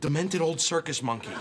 Play, download and share Demented Circus Monkey 2 original sound button!!!!
demented-circus-monkey-2.mp3